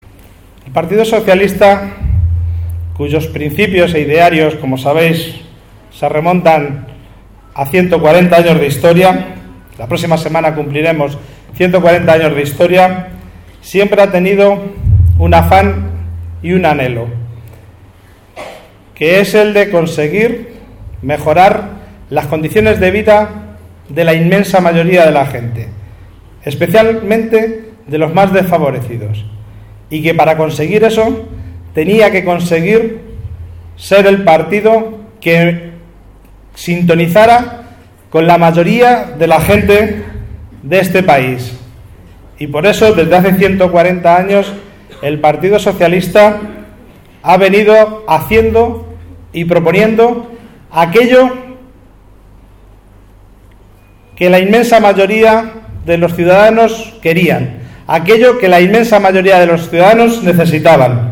Así lo pedía el número uno al Congreso y el también secretario de Organización del PSOE de Castilla-La Mancha durante el acto celebrado en Escalona que compartía con el secretario general del PSOE de la provincia de Toledo, Álvaro Gutiérrez, y el ex presidente de Castilla-La Mancha, José Bono, en la Casa de la Cultura de la localidad que se llenó con más de 450 personas.
Cortes de audio de la rueda de prensa